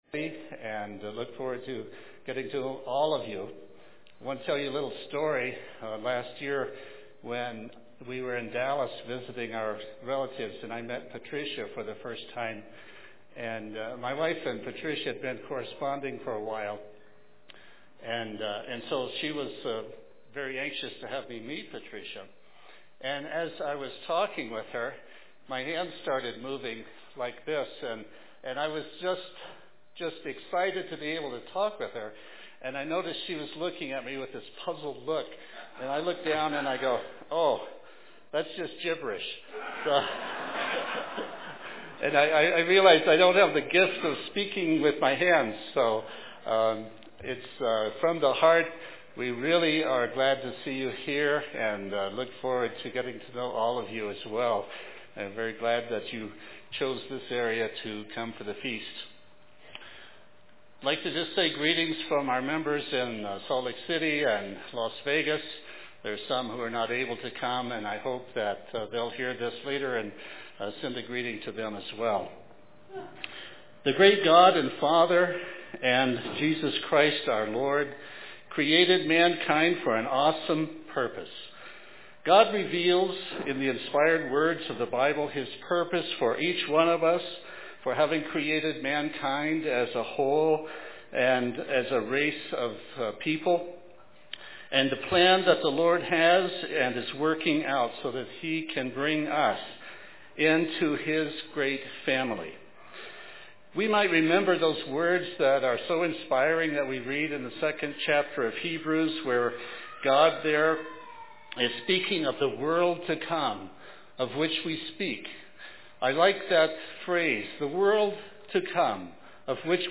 This sermon was given at the Steamboat Springs, Colorado 2013 Feast site.